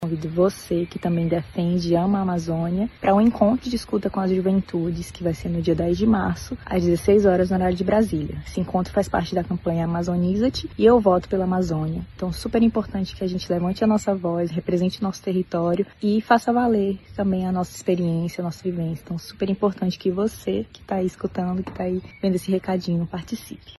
SONORA-ATIVISTA-.mp3